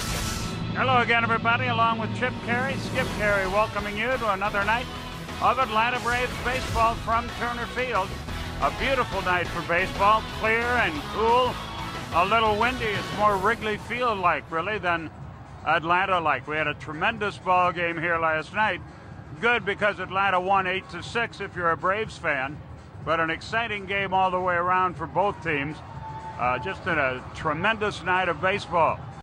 PLEASE LISTEN TO SKIP CARAY’S ORIGINAL VOICE.